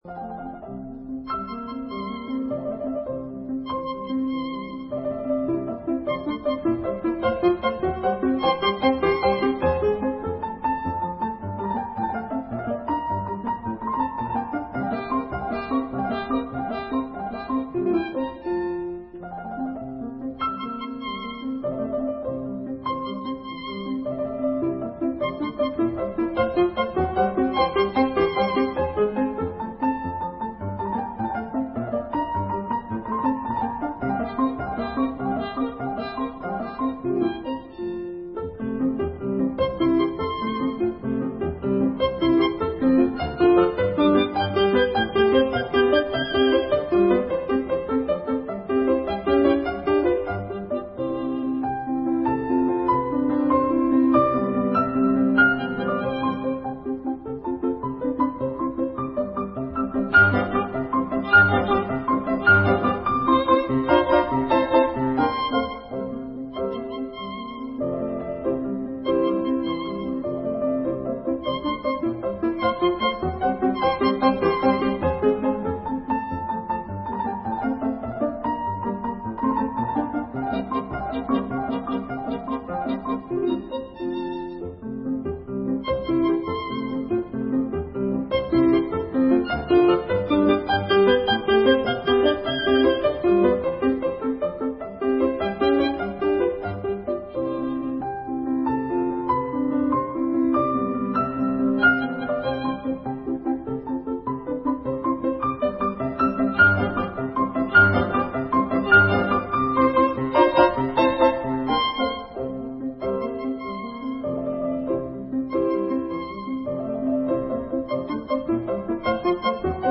Во второй же части сонаты, andante con variazioni, которую Позднышев прокомментировал так: